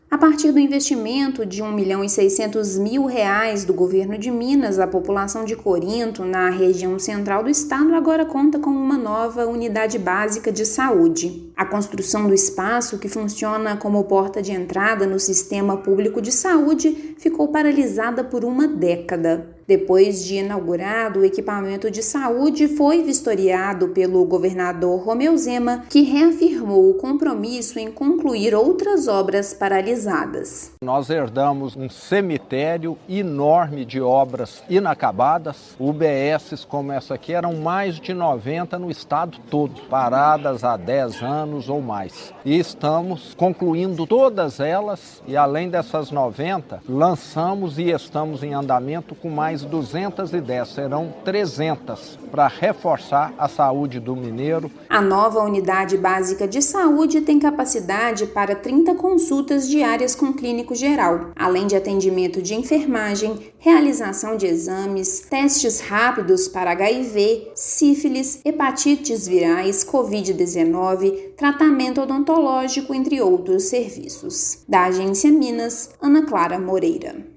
[RÁDIO] Governo de Minas vistoria funcionamento de UBS que ficou com obras paralisadas por dez anos em Corinto
Entrega das unidades faz parte da política de trazer os serviços de saúde para mais perto dos cidadãos. Ouça matéria de rádio.